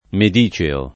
medeo] agg. — domin. oggi tra le persone cólte la pn. -eo (analoga a cesareo, ecc.): Tra la medicea ferrea catena [tra lla mede-a f$rrea kat%na] (Carducci); Chiusa ne’ suoi recinti la villa medìcea dorme [kL2Sa ne SU0i re©&nti la v&lla mede-a d0rme] (D’Annunzio) — un es. di medi©$o in rima, secondo una pn. un tempo ammessa (analoga a visconteo, giustinianeo, augusteo, ecc.): Penderanno li arazzi medicèi [pender#nno li ar#ZZi medi©$i] (D’Annunzio)